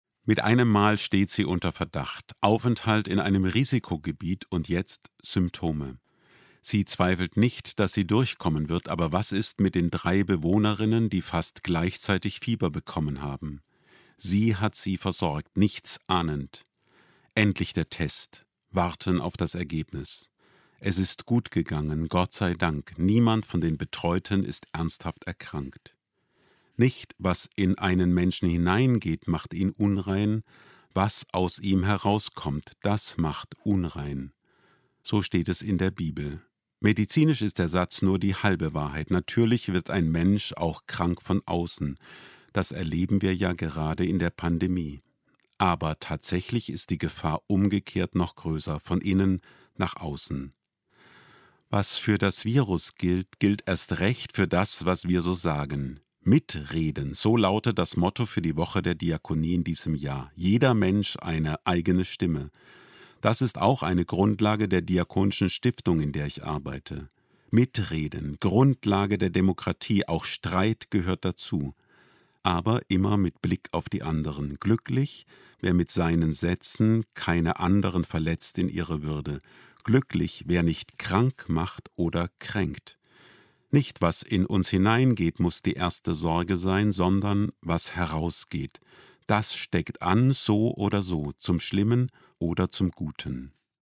Radioandacht in der Woche der Diakonie „Mitreden“